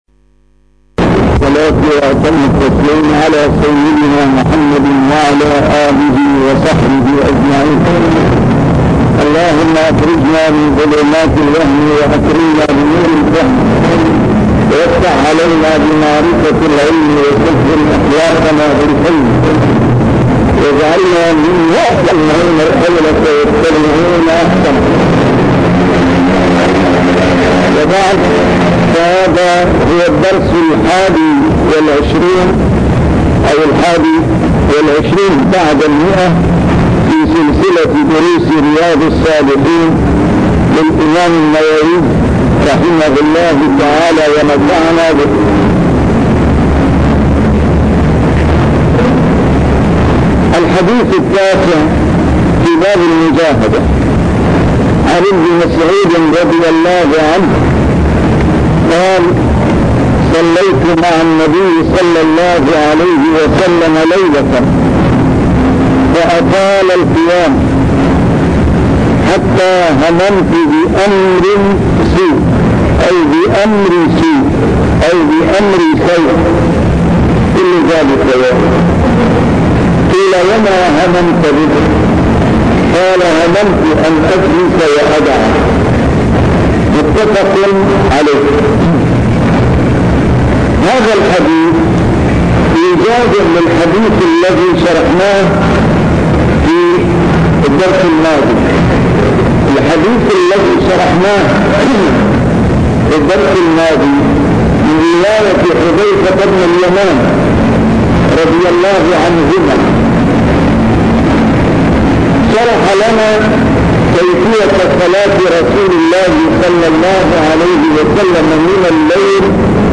A MARTYR SCHOLAR: IMAM MUHAMMAD SAEED RAMADAN AL-BOUTI - الدروس العلمية - شرح كتاب رياض الصالحين - 121- شرح رياض الصالحين: المجاهدة